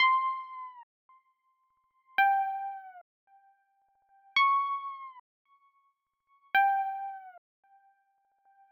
简单的诡异的钟声旋律 110 BPM
描述：一个用DX10、混响和Gross Beat制作的有点令人毛骨悚然的循环。
Tag: 110 bpm Hip Hop Loops Synth Loops 1.47 MB wav Key : Unknown